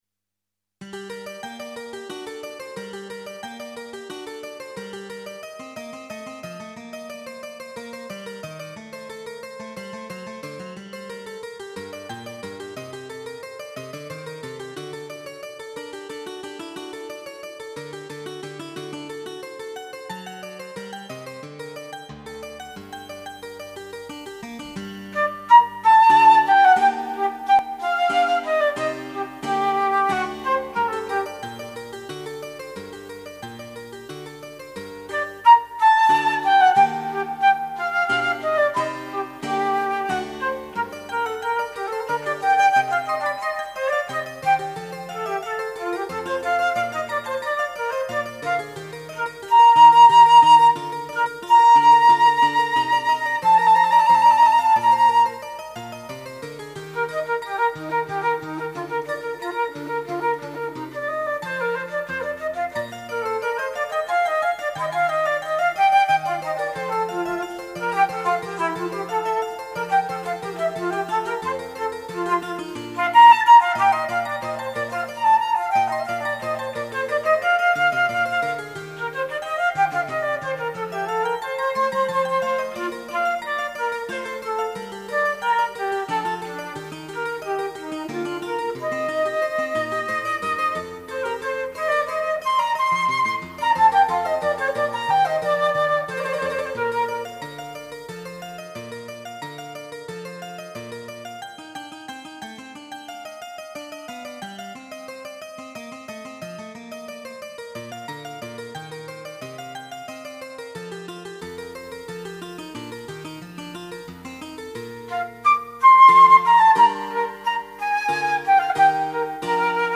昔公開した私の演奏です。
Flute Sonata G moll BWV1020 I (J. S. Bach)